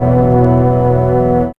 1906R STRPAD.wav